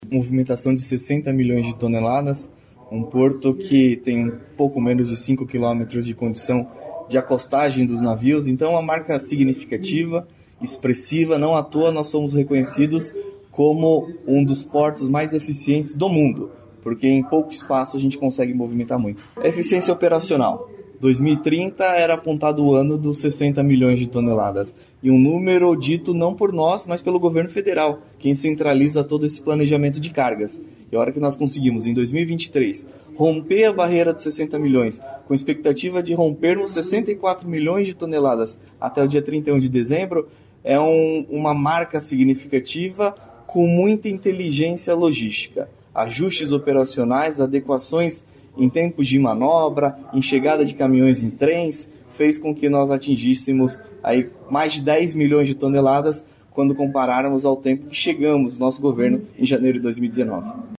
Sonora do diretor-presidente da Portos do Paraná, Luiz Fernando Garcia, sobre o recorde de movimentação de cargas dos portos paranaenses em 2023